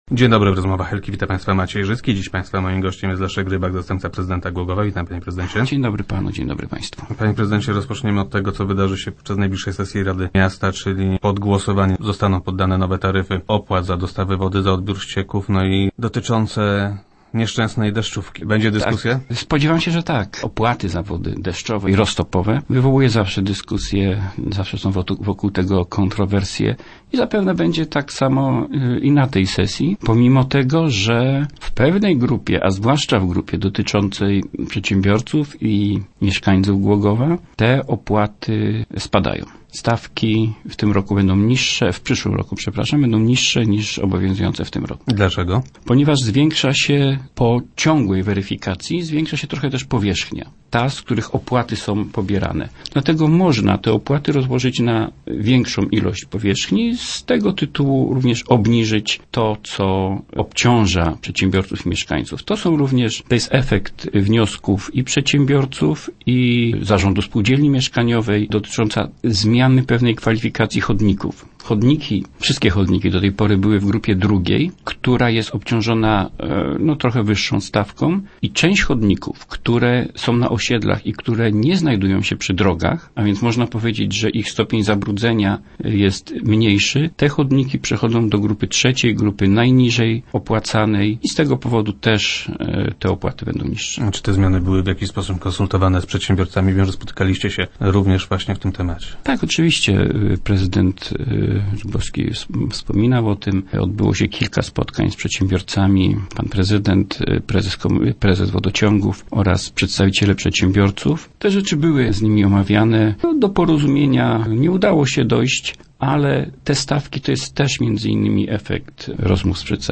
Zostaną na nich zainstalowane specjalne lampy. - Trwa właśnie odbiór techniczny pierwszego takiego oświetlenia - informuje Leszek Rybak, zastępca prezydenta Głogowa, który był gościem Rozmów Elki.